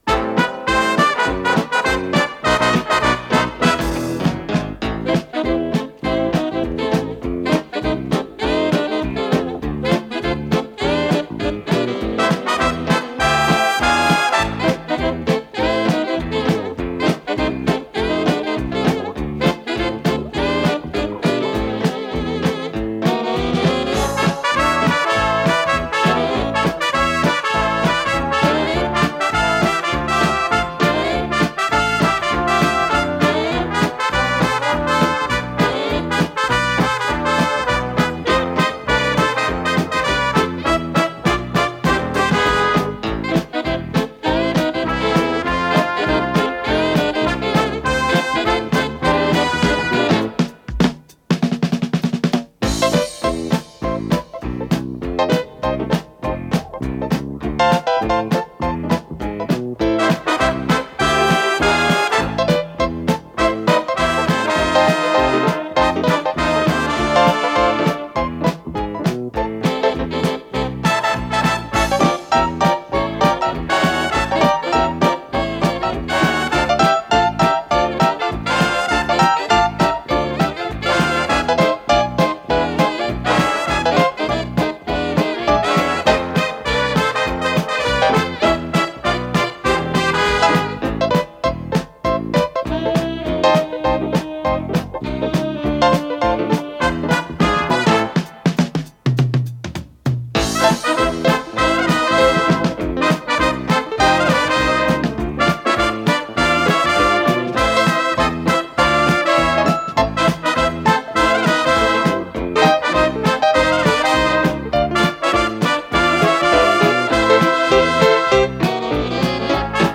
ля минор
ВариантДубль моно